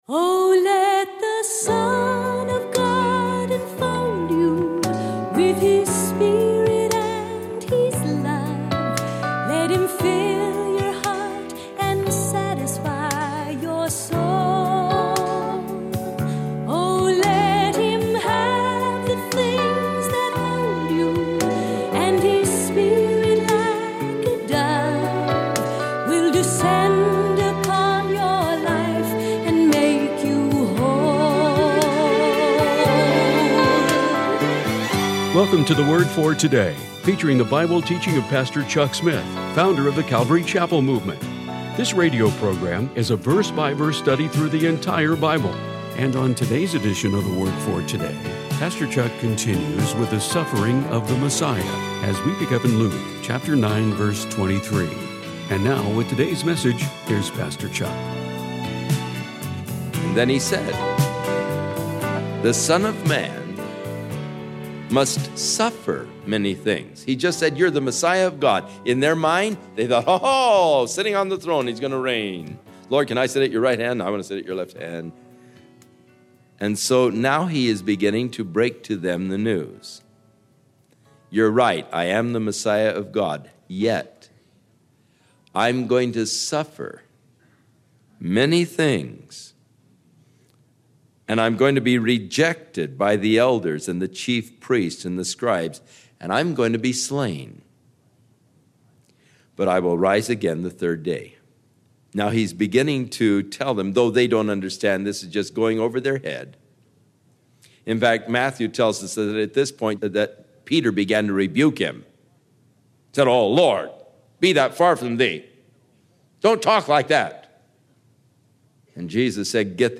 This radio program is a verse by verse study through the entire Bible.